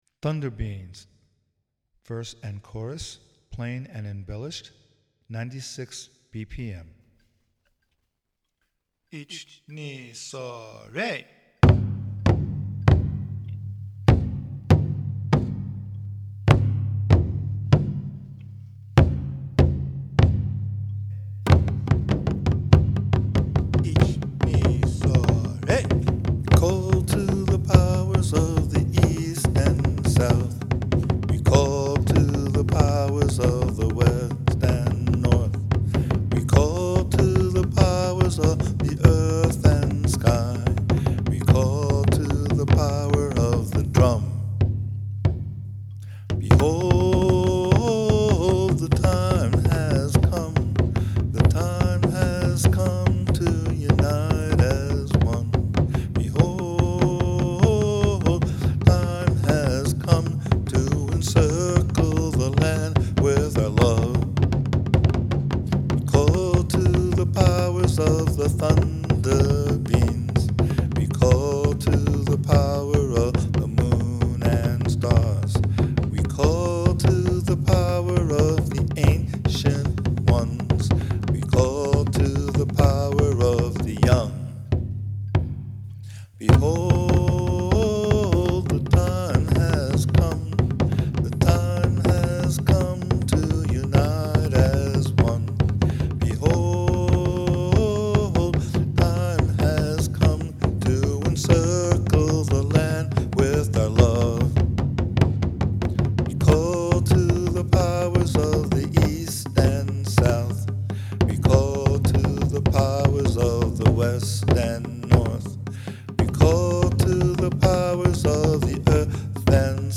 It is also quite upbeat and energetic.
A multi-track recording of the basic arrangement of the Verse and Chorus of Kaminari Tamashi at 96 BPM, with the chu-daiko, Odaiko, and shimedaiko with Vocals and Coda. Plain and “embellished” versions of the Verse and Chorus are demonstrated.